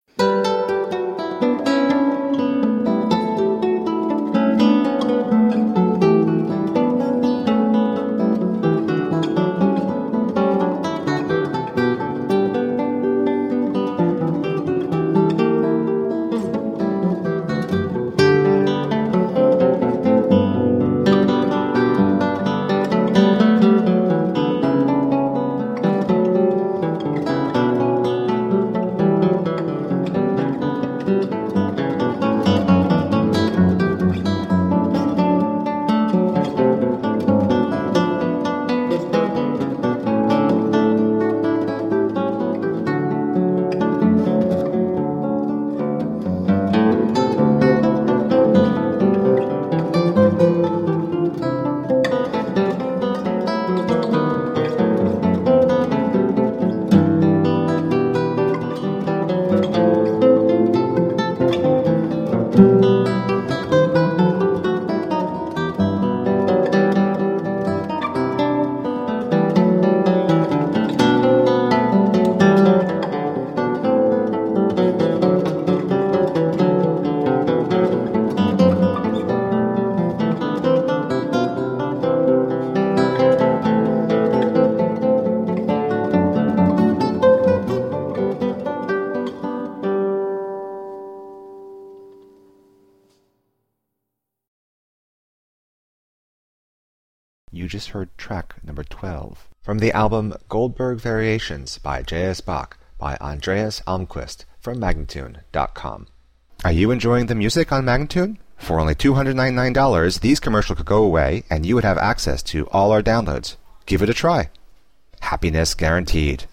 Elegant classical guitar